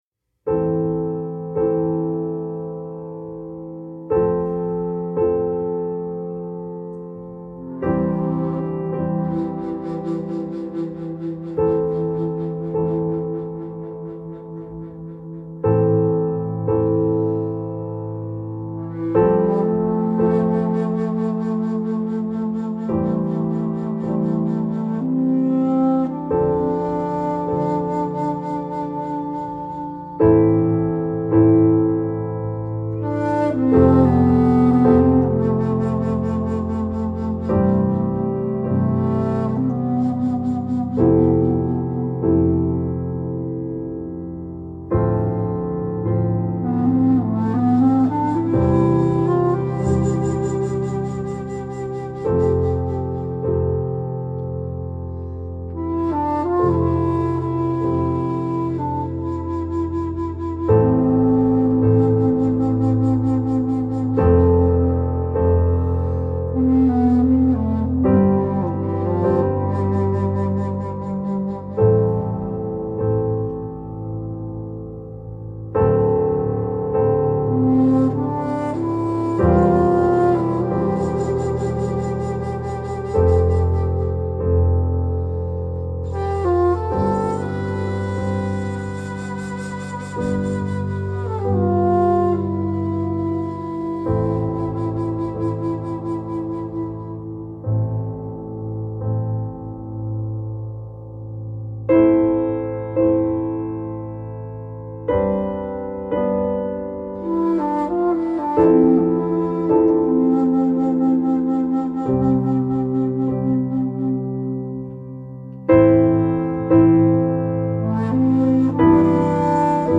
موسیقی کنار تو